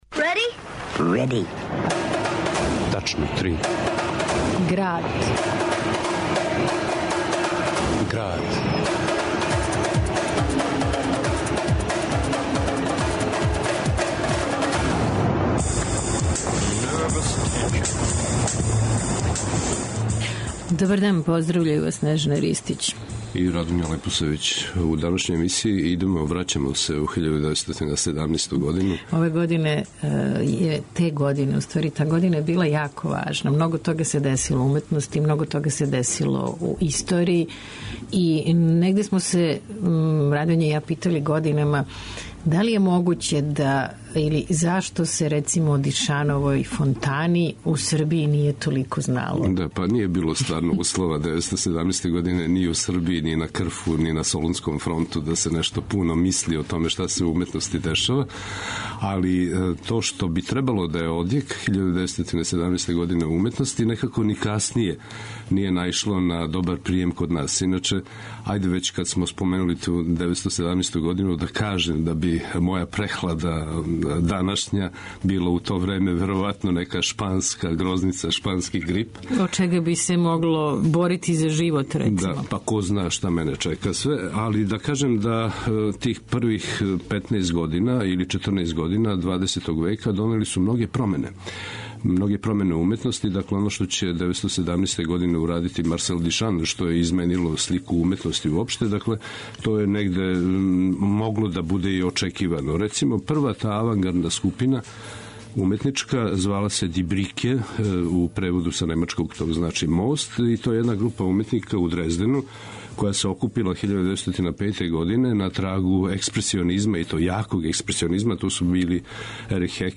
У Граду , уз фичер О Фонтани и корњачама - што је заправо документарни радио-есеј о рату, уметности, револуцијама, Србији, Европи, свету у 20. веку...